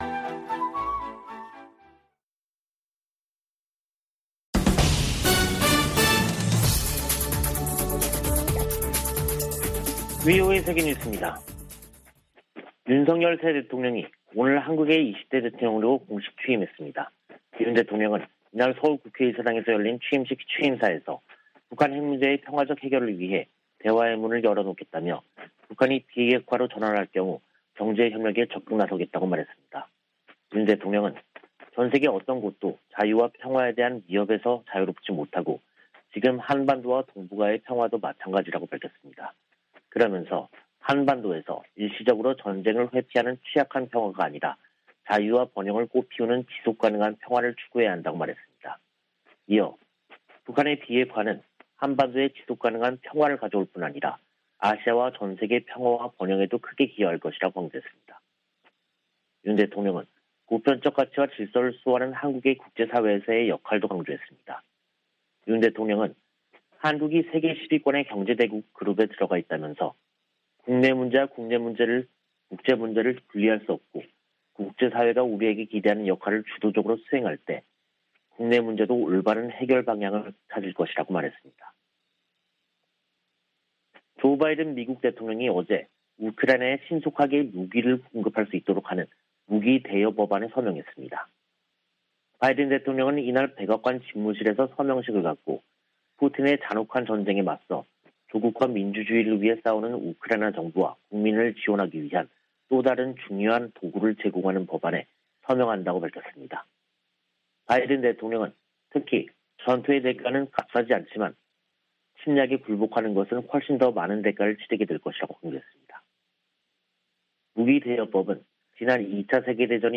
VOA 한국어 간판 뉴스 프로그램 '뉴스 투데이', 2022년 5월 10일 2부 방송입니다. 윤석열 한국 대통령이 10일 취임했습니다. 윤 대통령은 비핵화로 전환하면 북한 경제를 획기적으로 개선할 계획을 준비하겠다고 밝혔습니다.